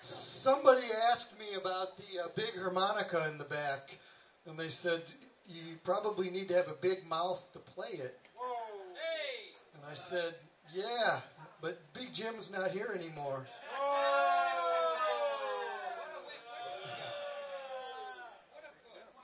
5th OTS Recital - Winter 2005 - rjt_4232